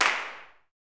CLAPSUTC7.wav